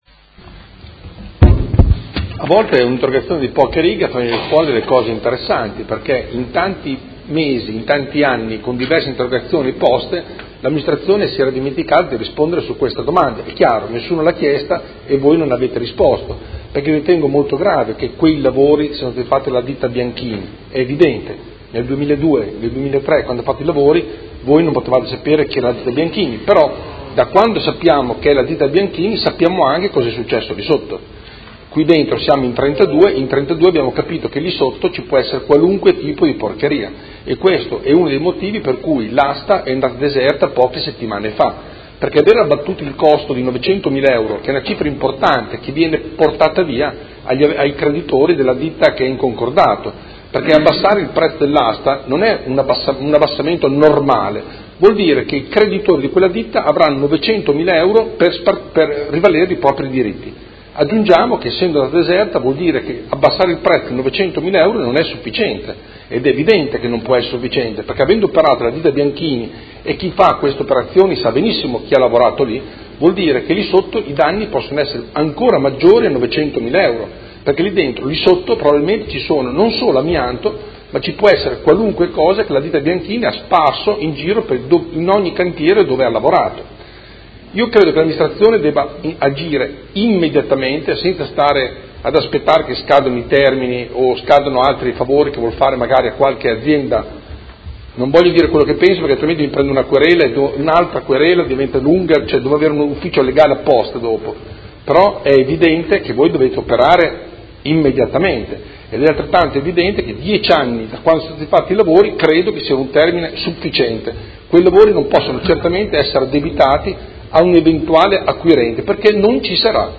Seduta dell'11/01/2018 Replica a risposta Assessore Giacobazzi. Interrogazione del Consigliere Galli (FI) avente per oggetto: La rotonda di Via Emilia Est contiene amianto; chi ha realizzato i lavori?